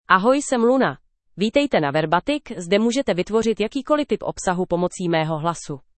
FemaleCzech (Czech Republic)
Luna is a female AI voice for Czech (Czech Republic).
Voice sample
Listen to Luna's female Czech voice.
Female
Luna delivers clear pronunciation with authentic Czech Republic Czech intonation, making your content sound professionally produced.